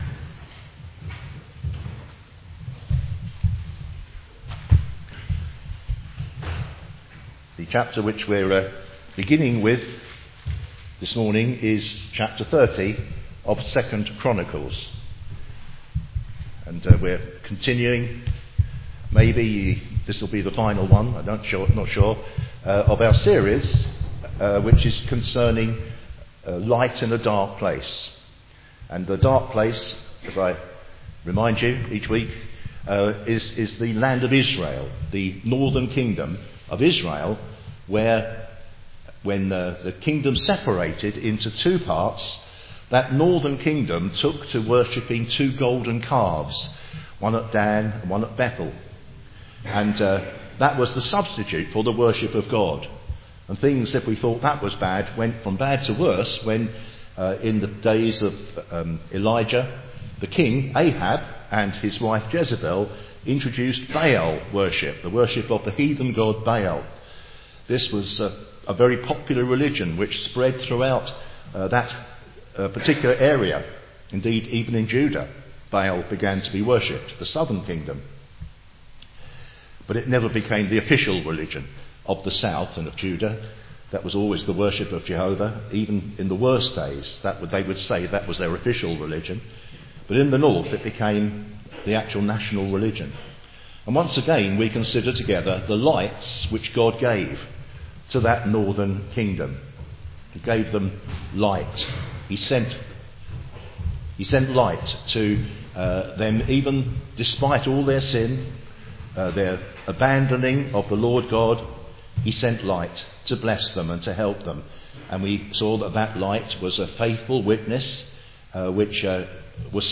Teaching and Gospel sermons on 2 Chronicles